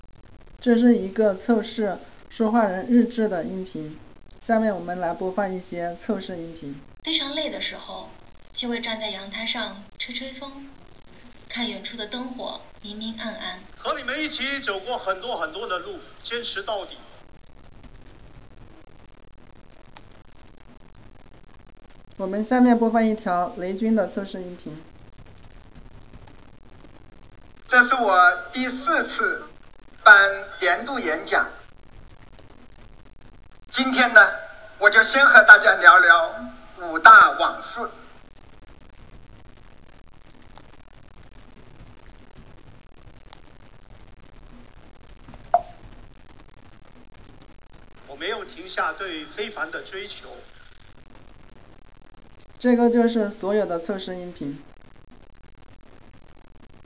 0-four-speakers-zh.wav